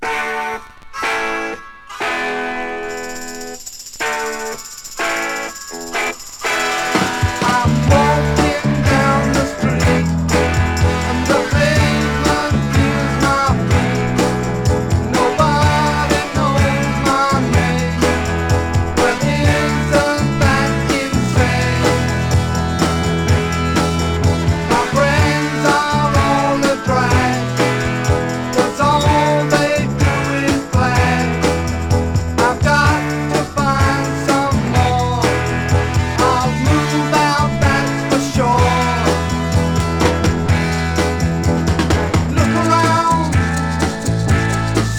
Rock, Psychedelic Rock　USA　12inchレコード　33rpm　Stereo